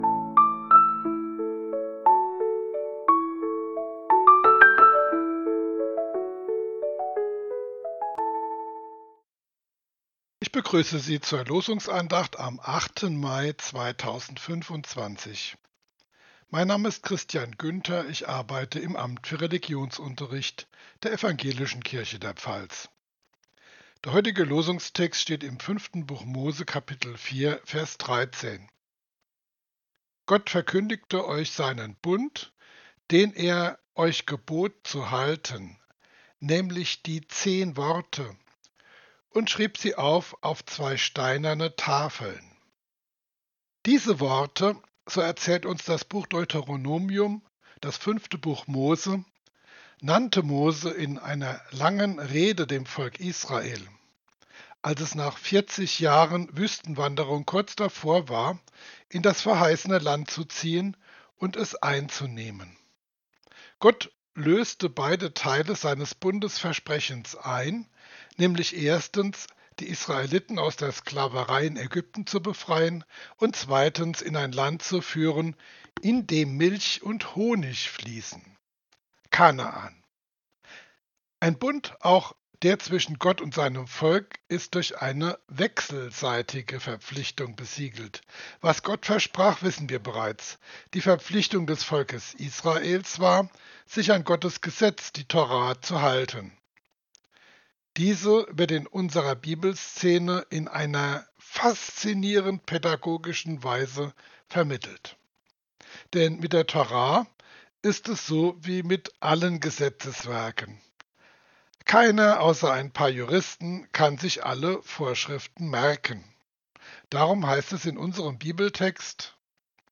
Losungsandacht für Donnerstag, 08.05.2025